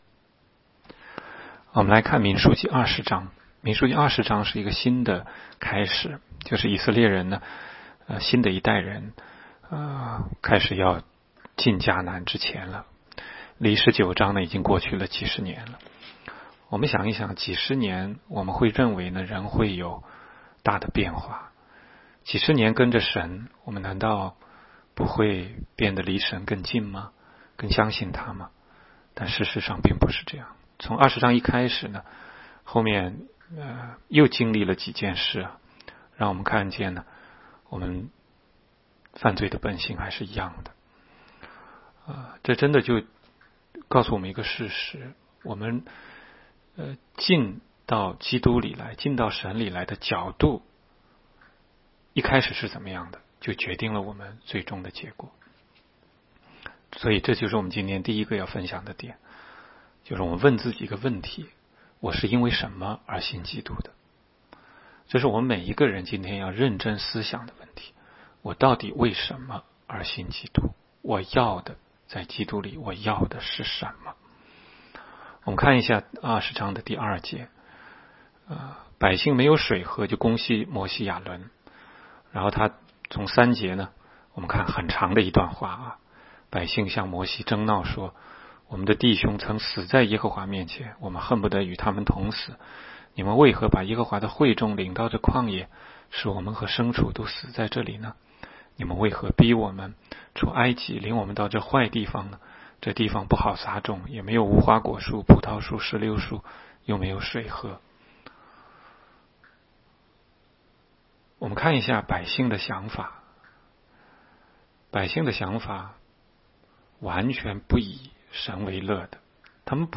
16街讲道录音 - 每日读经-《民数记》20章